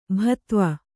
♪ bhatva